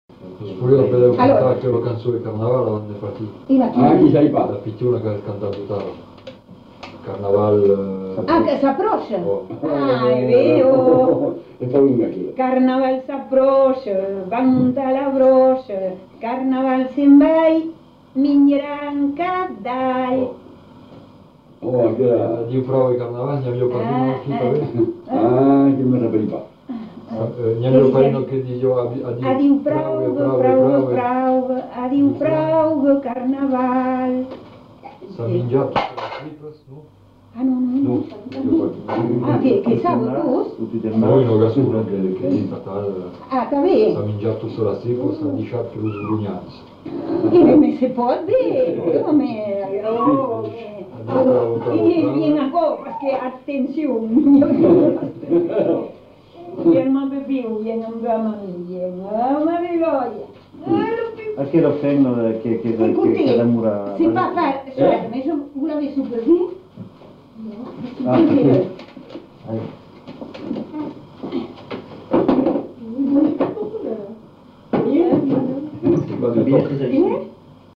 Aire culturelle : Marmandais gascon
Lieu : Tonneins
Genre : chant
Effectif : 1
Type de voix : voix de femme
Production du son : chanté
Classification : chanson de carnaval